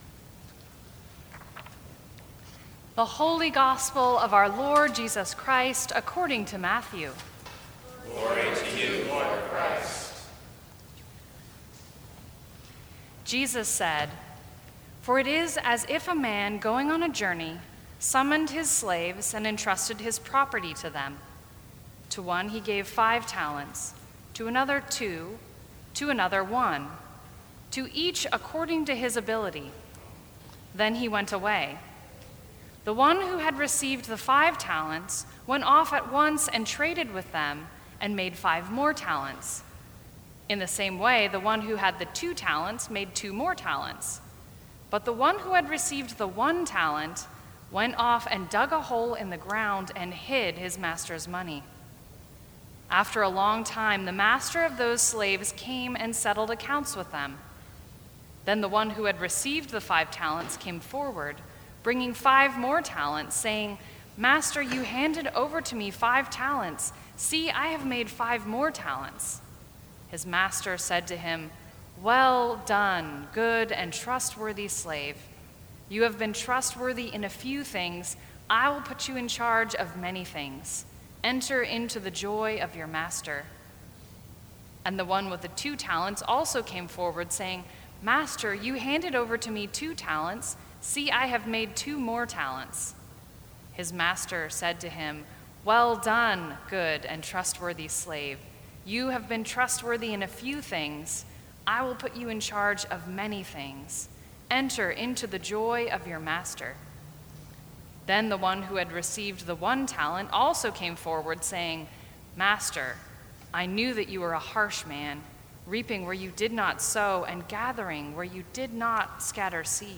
Sermons from St. Cross Episcopal Church The Whole Story Nov 20 2017 | 00:14:07 1x 00:00 / 00:14:07 Subscribe Share Apple Podcasts Spotify Overcast RSS Feed Share Link Embed